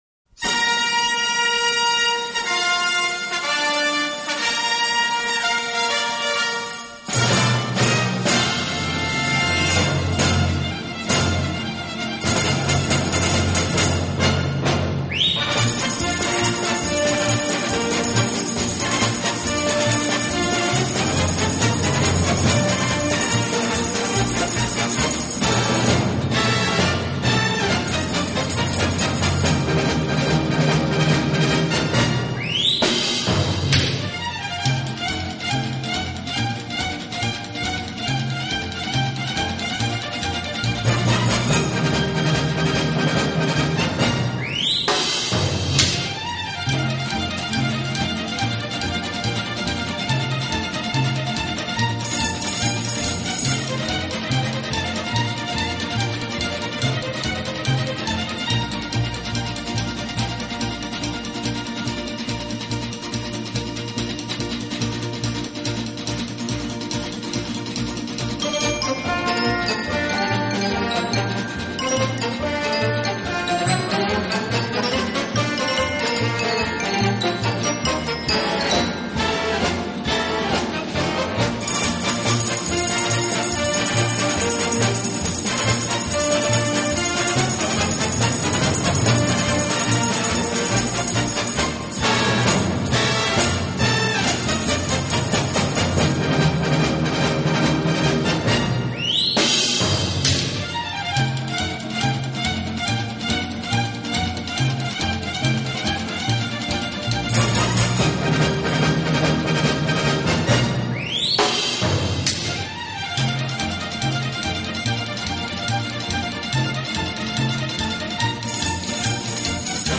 【轻音乐专辑】
album of Mexican-influenced instrumentals